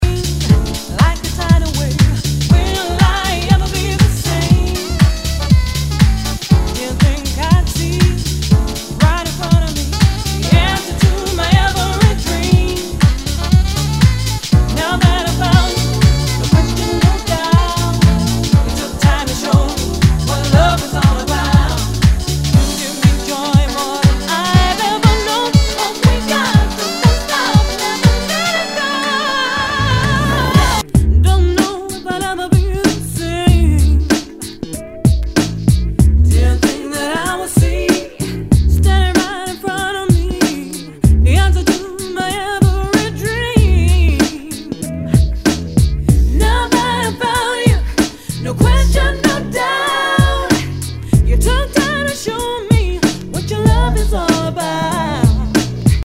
HOUSE/TECHNO/ELECTRO
ナイス！ヴォーカル・ハウス / UK R&B！